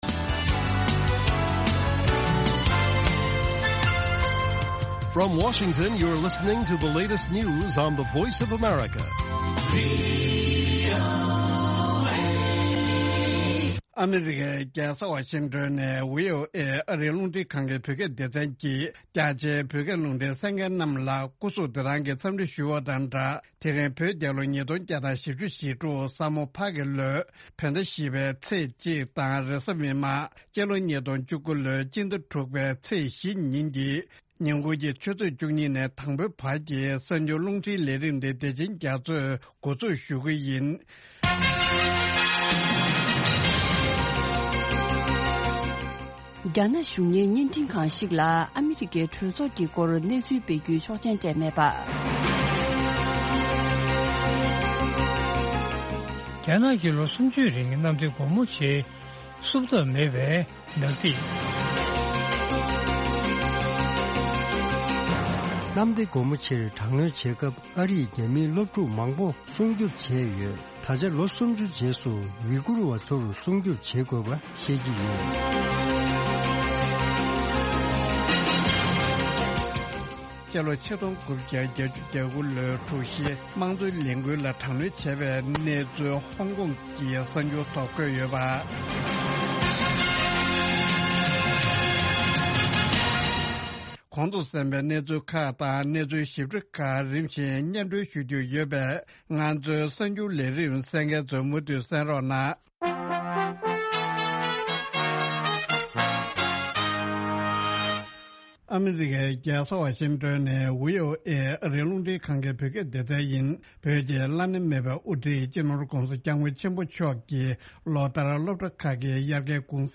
Afternoon Show Broadcast daily at 12:00 Noon Tibet time, the Lunchtime Show presents a regional and world news update, followed by a compilation of the best correspondent reports and feature stories from the last two shows. An excellent program for catching up on the latest news and hearing reports and programs you may have missed in the morning or the previous night.